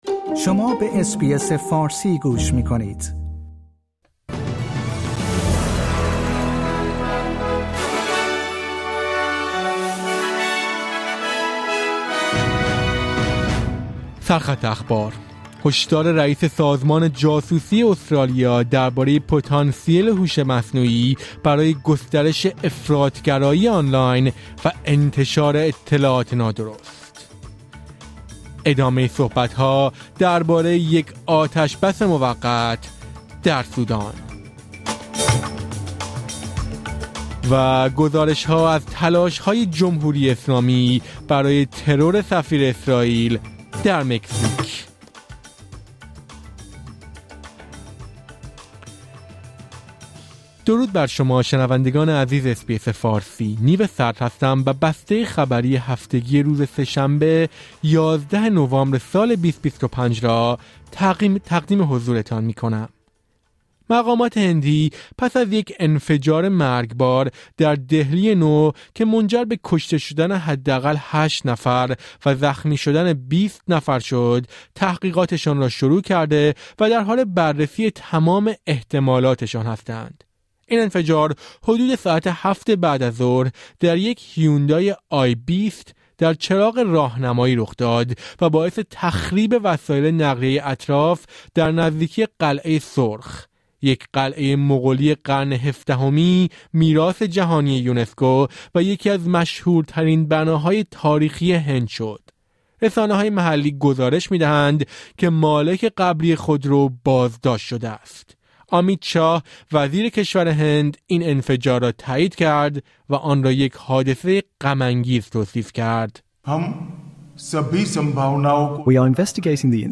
در این پادکست خبری مهمترین اخبار هفته منتهی به سه‌شنبه ۴ نوامبر ۲۰۲۵ ارائه شده است.